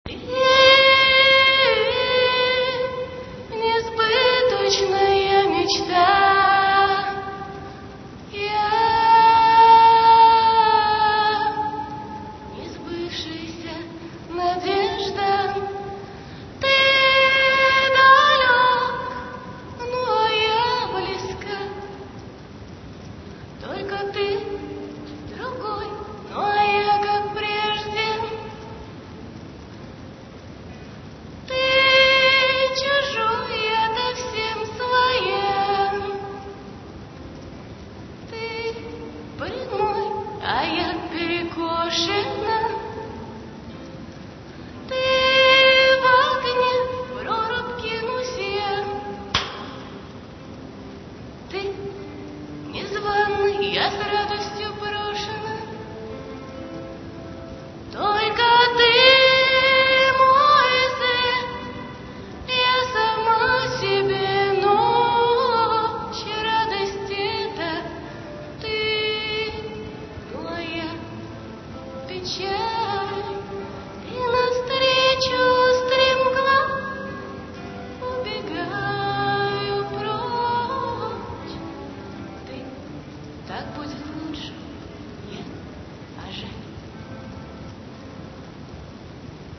262 kb, авторское исполнение